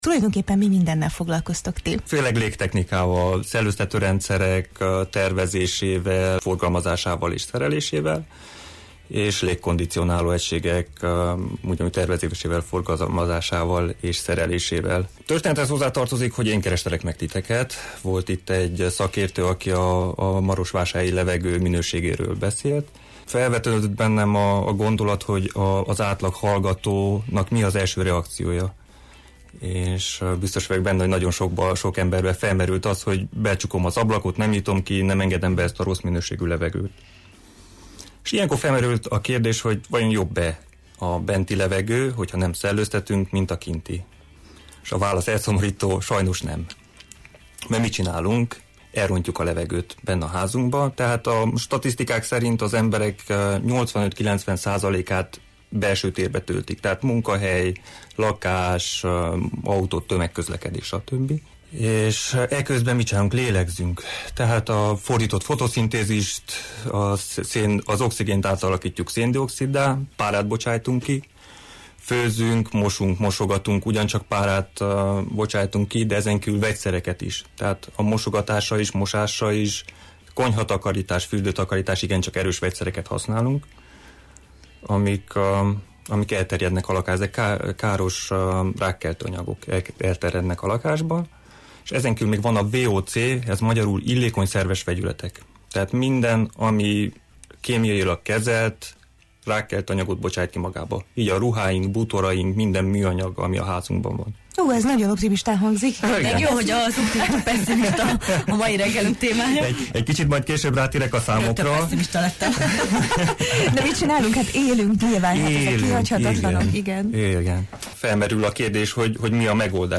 Erről a kérdésről beszélgettünk ma a Jó reggelt, Erdély!-ben